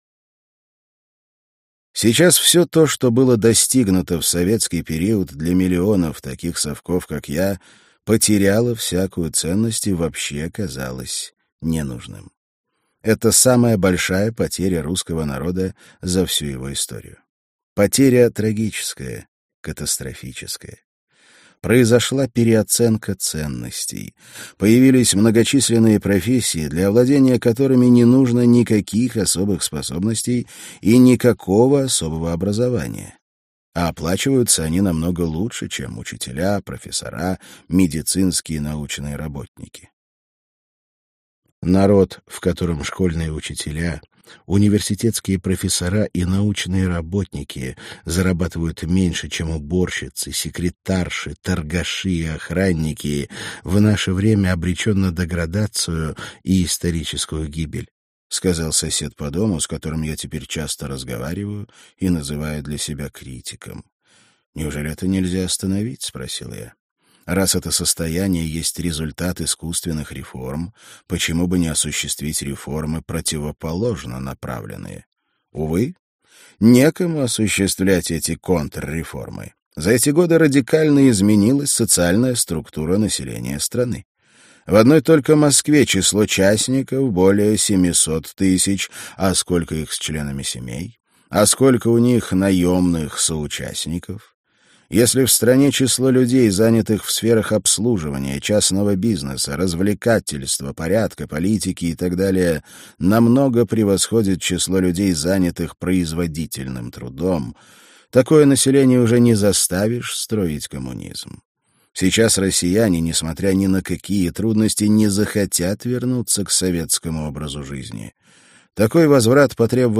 Аудиокнига Русская трагедия. Часть 1 | Библиотека аудиокниг